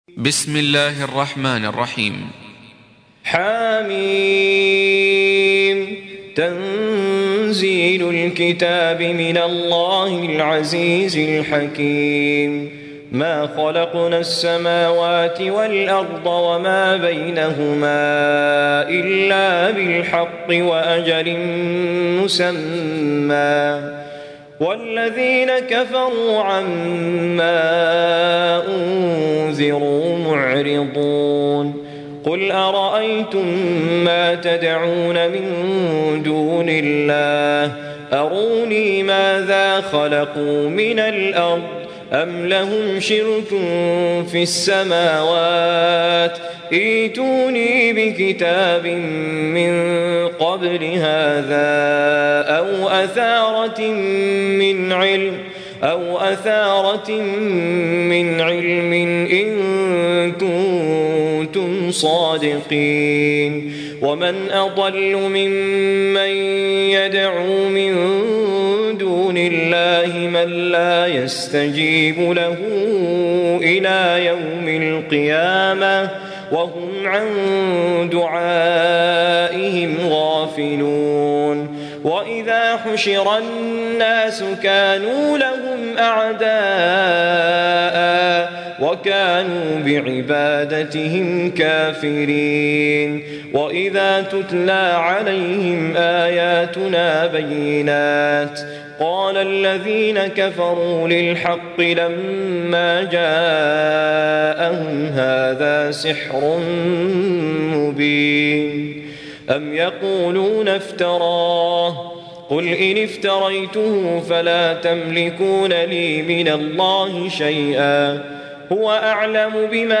46. Surah Al-Ahq�f سورة الأحقاف Audio Quran Tarteel Recitation